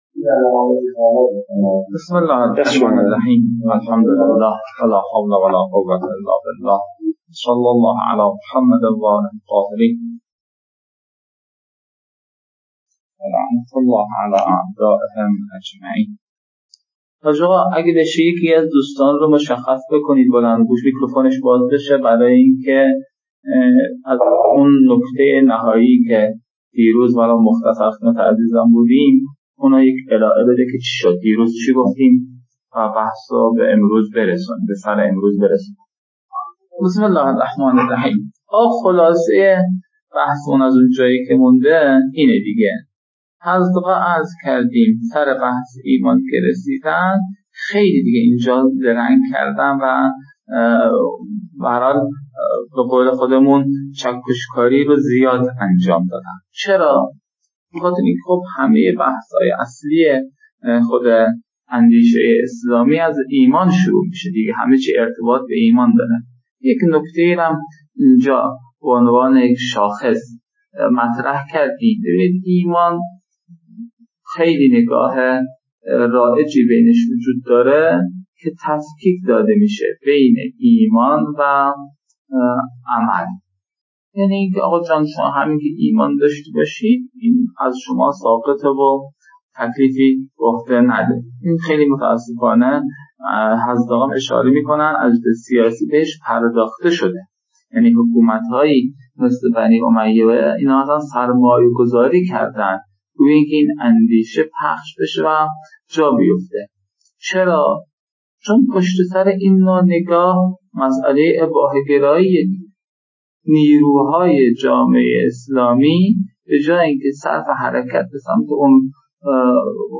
🔸 لازم به‌ذکر است که نتیجه این رویکرد، صرف پاره‌ای از بازه کلاس به رفت‌وبرگشت مبحث بین استاد و مخاطبان است که در کنار مجازی برگزارشدن کلاس، حوصله خاصی را در گوش دادن می‌طلبد. (البته فایل‌های صوتی بارها ویرایش شده‌اند تا کیفیت بهتر و مفیدتری داشته باشند.)